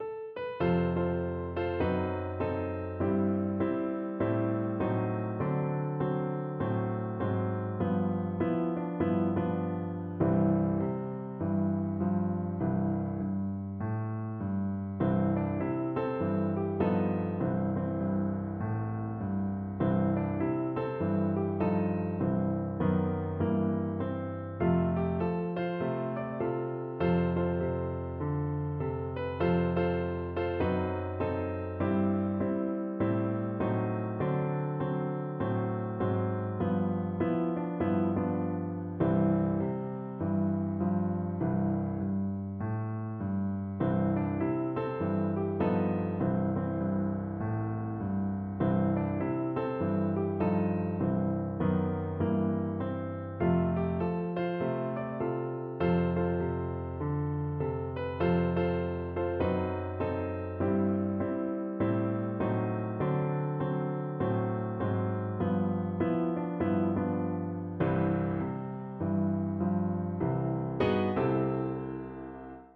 "Frankie and Johnny" (sometimes spelled "Frankie and Johnnie"; also known as "Frankie and Albert" or just "Frankie") is a traditional American popular song.
Moderate Swing = c. 100
4/4 (View more 4/4 Music)
Arrangement for Piano with vocal line
C major (Sounding Pitch) (View more C major Music for Piano )
Piano  (View more Easy Piano Music)
Traditional (View more Traditional Piano Music)